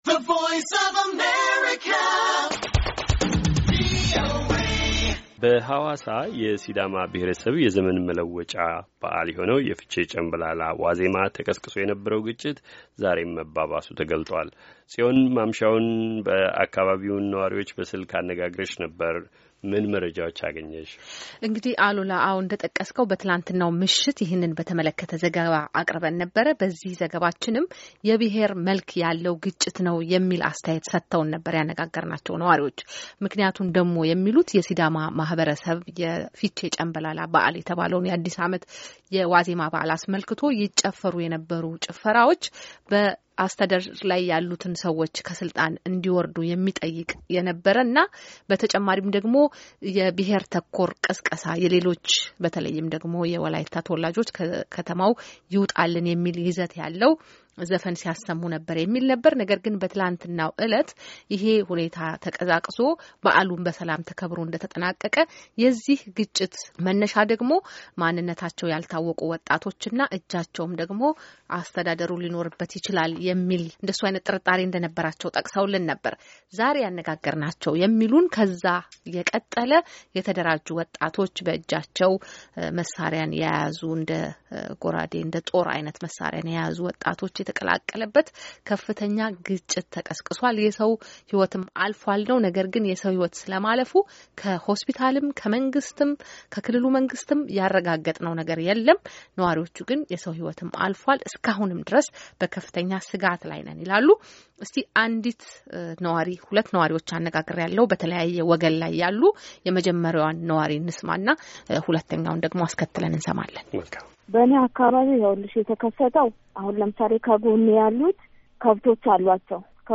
የሀዋሳ ከተማ የነዋሪዎች ግጭት - ነዋሪዎች አነጋግረናል